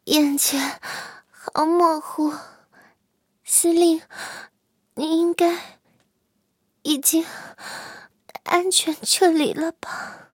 M6被击毁语音.OGG